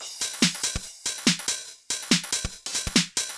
Index of /musicradar/uk-garage-samples/142bpm Lines n Loops/Beats
GA_BeatA142-09.wav